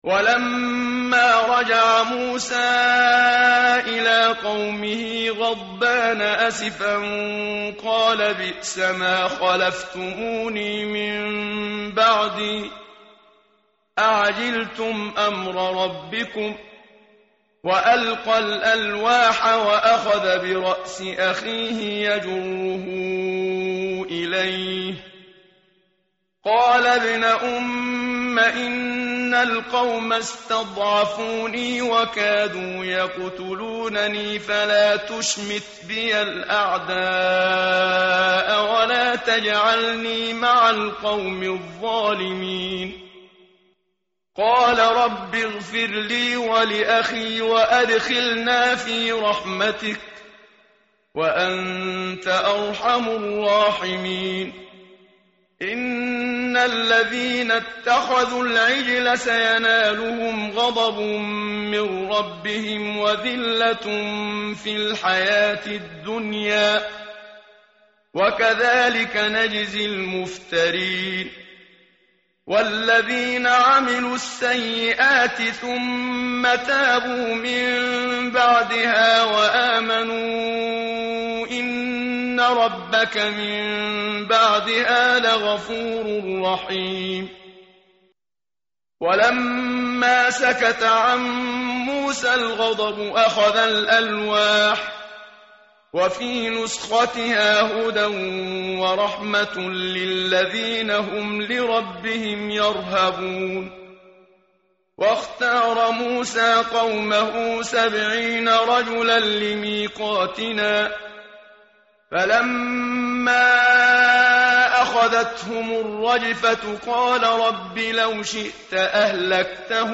متن قرآن همراه باتلاوت قرآن و ترجمه
tartil_menshavi_page_169.mp3